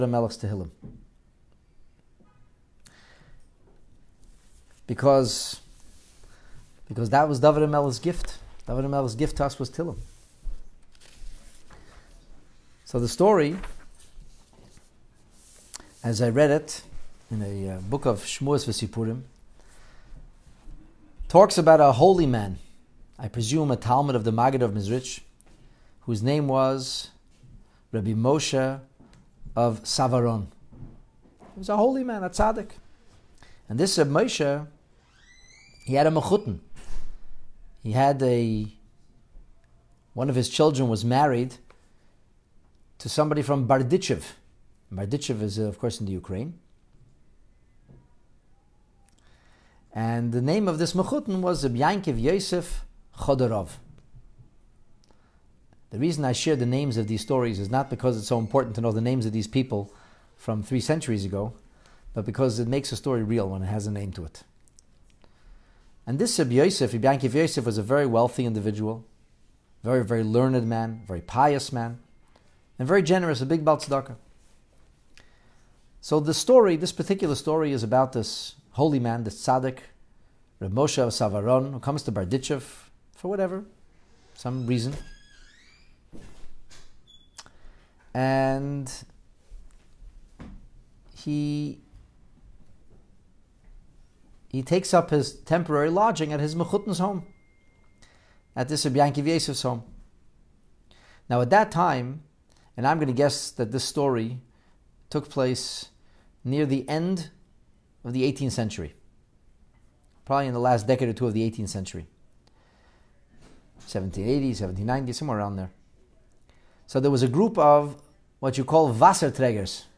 Story told at a motzei shabbat Farbrengen